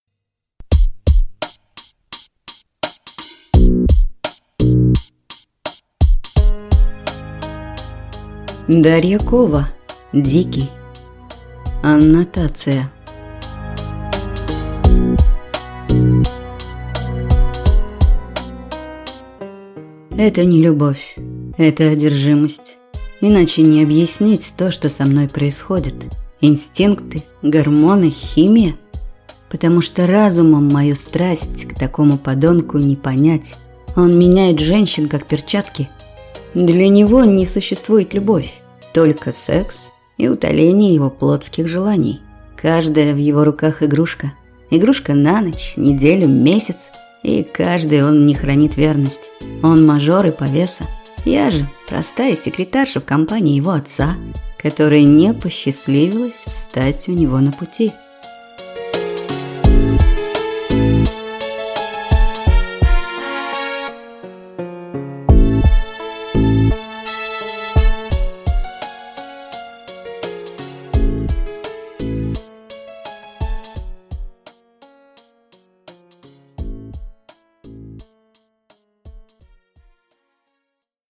Аудиокнига Дикий | Библиотека аудиокниг
Прослушать и бесплатно скачать фрагмент аудиокниги